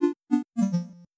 Success2.wav